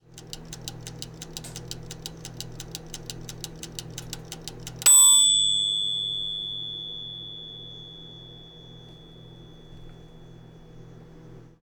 House_toaster oven_ding
cartoon ding house kitchen toaster-oven sound effect free sound royalty free Movies & TV